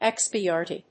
音節ex・pi・a・to・ry 発音記号・読み方
/ékspiət`ɔːri(米国英語)/